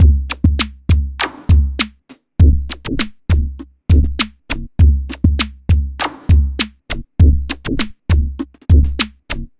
machine 100bpm 01.wav